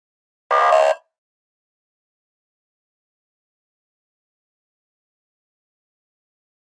Flash Alarm Low Frequency Electronic Double Buzz